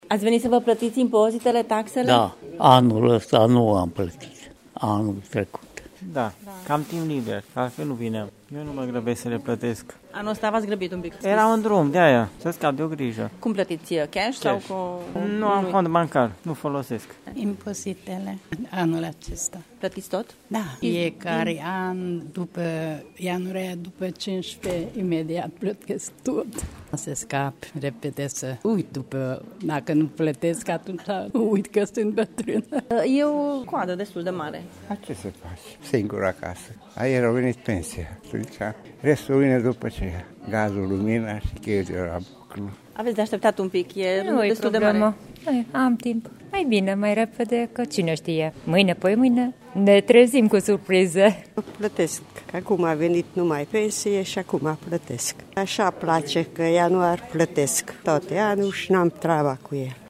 Câteva zeci de târgumureșeni așteptau la coadă în această dimineață la Primăria Târgu Mureș să-și plătească dările locale la unul din cele patru ghișee care sunt deschise aici. În majoritate pensionari, spun că au așteptat să le vină pensiile pentru a putea scăpa de o grijă: